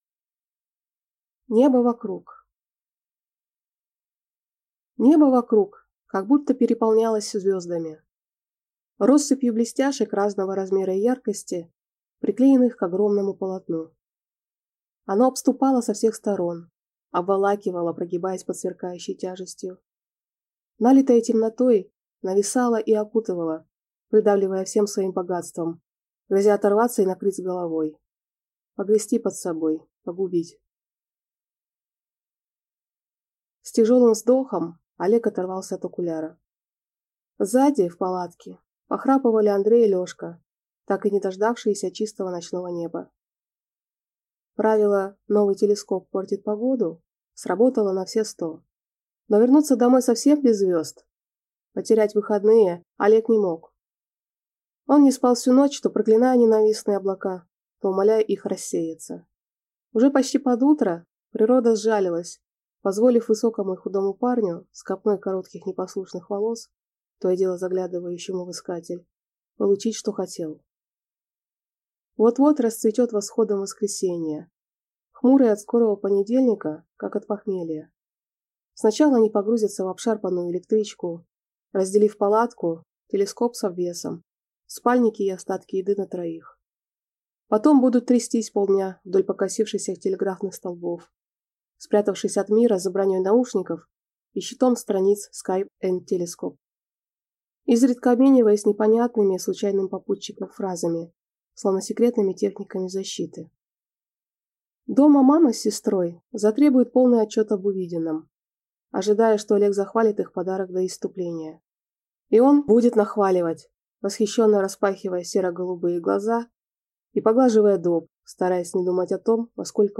Аудиокнига Небо вокруг | Библиотека аудиокниг